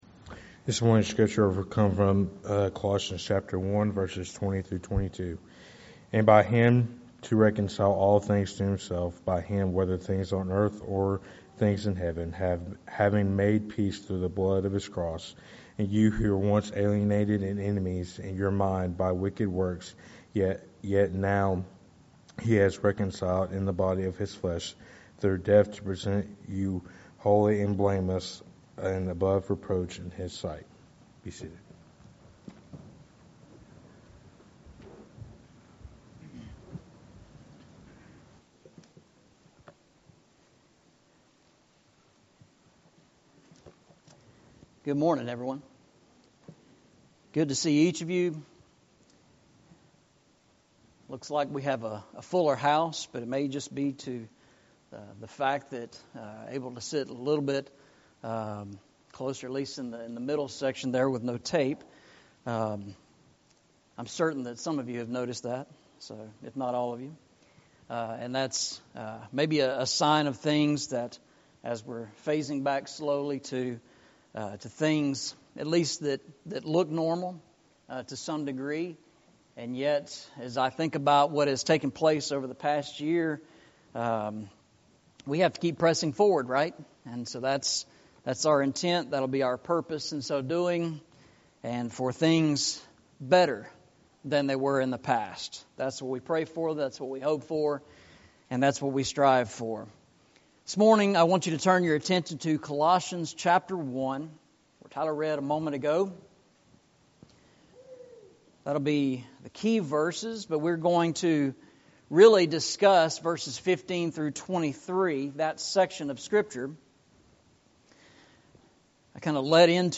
Eastside Sermons Passage: Colossians 1:20-22 Service Type: Sunday Morning « The Tabernacle